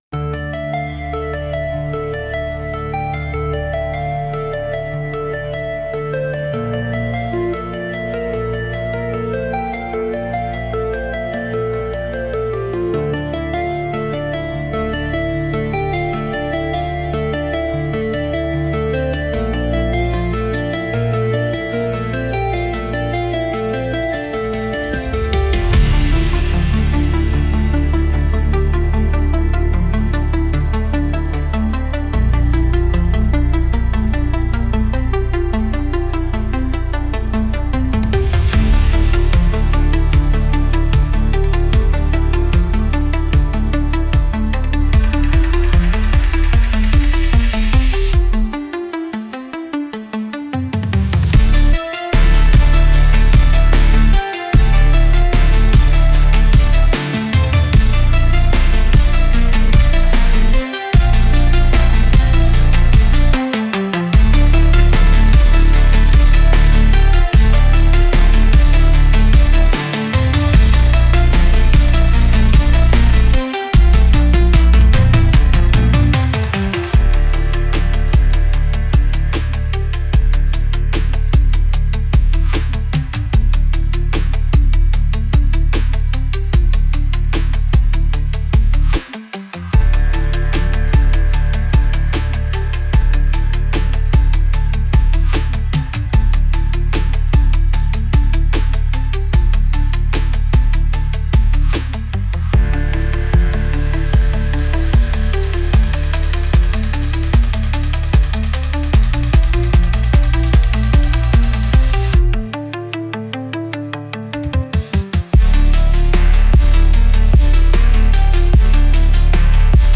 Genre: Electronic Mood: Upbeat Editor's Choice